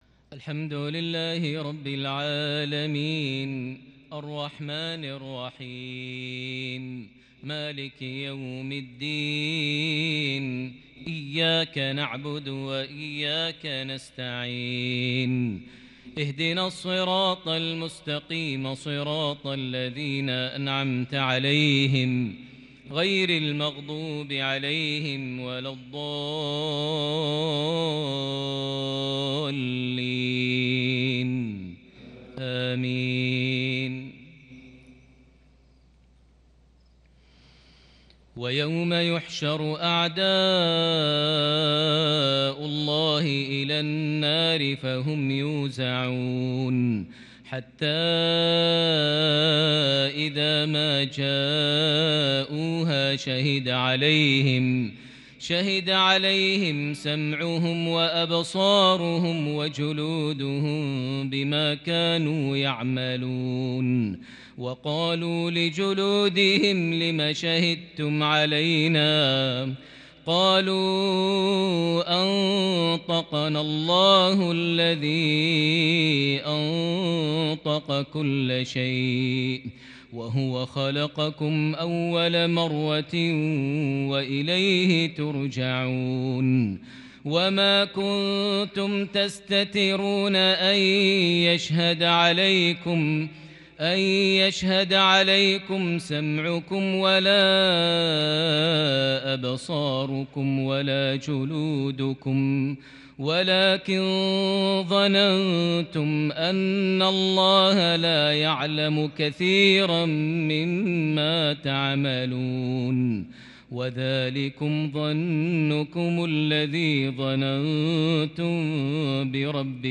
( وقالوا لجلودهم لم شهدتم علينا) عشائية شجية بالكرد من سورة فصلت (19-32) | 22 شعبان 1442هـ > 1442 هـ > الفروض - تلاوات ماهر المعيقلي